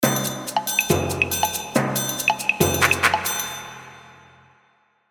打击乐循环
描述：声学打击乐
Tag: 140 bpm Acoustic Loops Percussion Loops 873.82 KB wav Key : Unknown